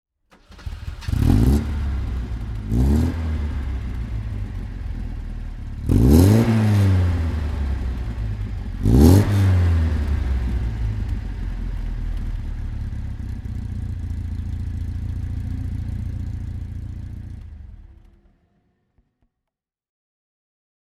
Alfa Romeo Giulia GTC (1966) - the 1600cc twin-camshaft engine with 105 hpAlfa Romeo Giulia GTC (1966) - the 1600cc twin-camshaft engine with 105 hp
Alfa Romeo Giulia GTC (1966) - Starten und Leerlauf
Alfa_Romeo_Giulia_GTC_1966.mp3